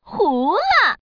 Index of /guizhou_ceshi_ypc/update/1677/res/sfx/woman/